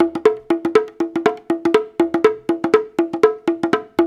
120 -UDU B07.wav